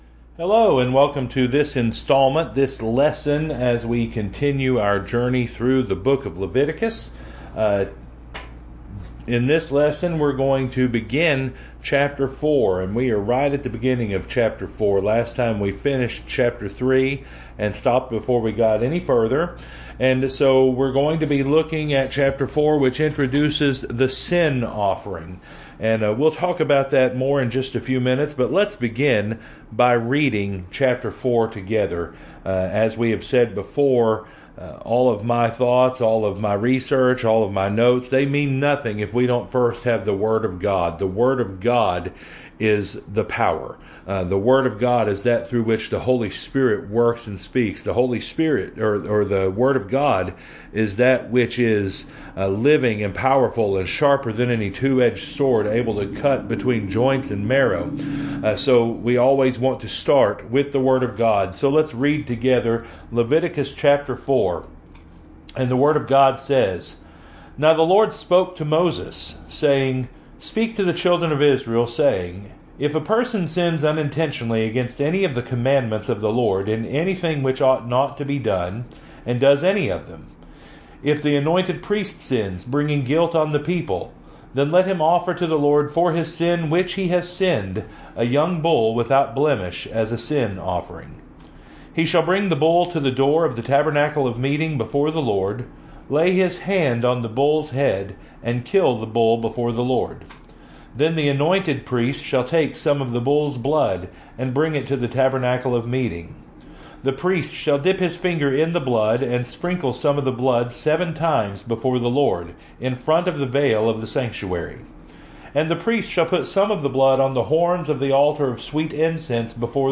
Wednesday Evening Bible Study